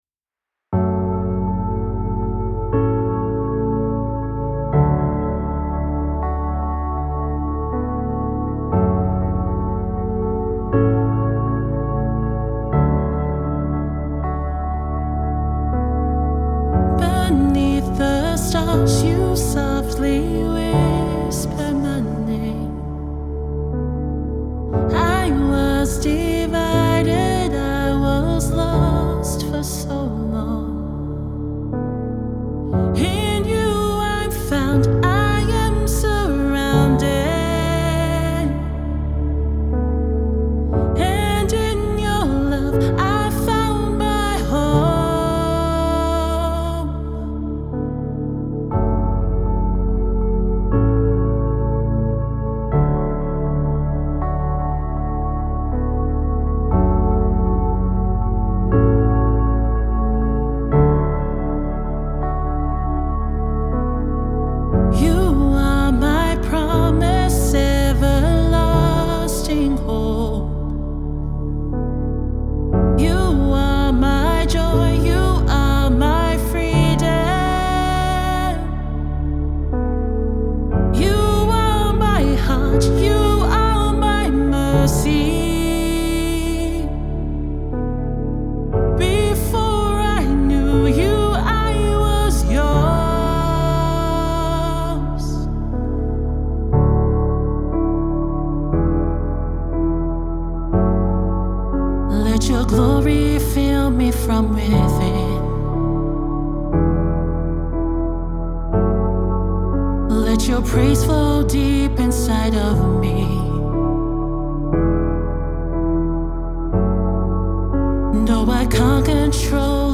fun song